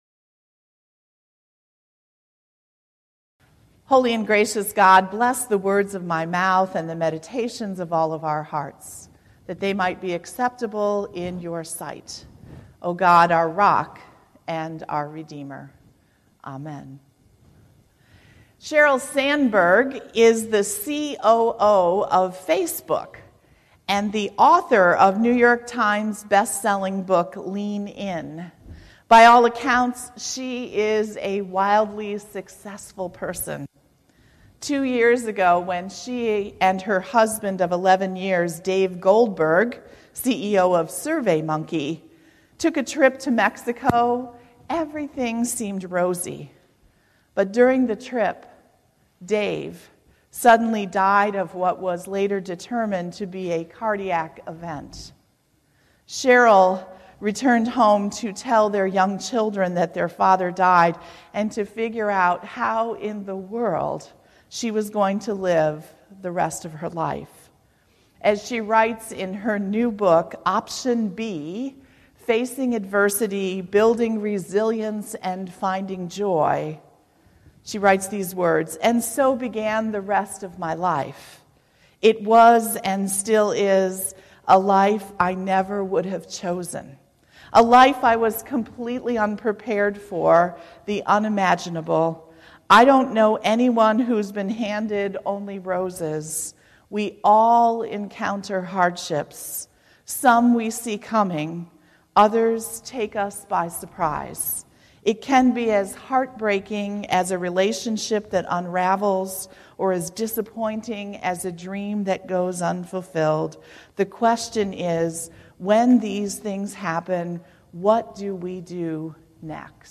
6/11/17 Sermon, “Plan B”